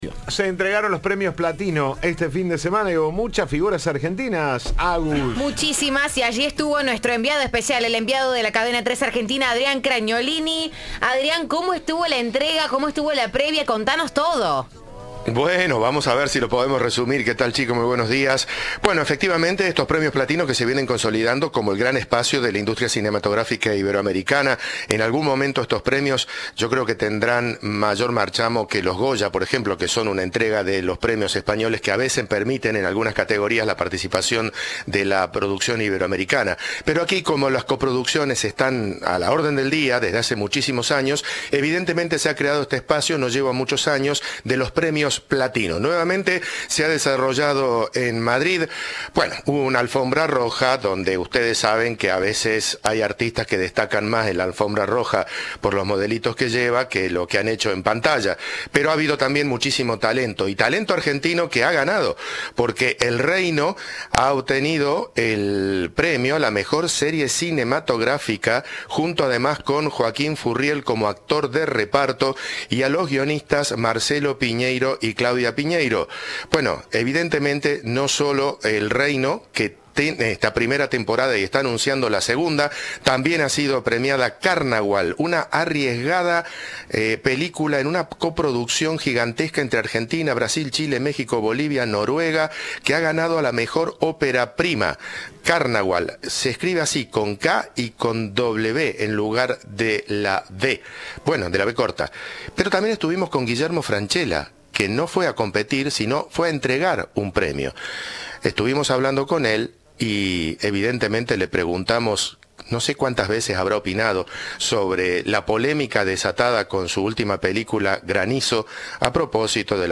durante la gala de la entrega de los premios Platino a la producción cinematográfica iberoamericana que se llevó a cabo el domingo en el Teatro Municipal Ifema de Madrid.